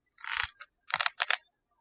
木地板吱吱作响
描述：木地板吱吱声：木地板吱吱作响，皮靴吱吱作响，吱吱作响。使用ZOOMH4n麦克风录制，在音响室中录制。
标签： 吱吱 地板 木材 靴子 OWI 吱吱 脚步 硬木 硬木地板 嘎嘎作响 吱吱响 叽叽嘎嘎 行走
声道立体声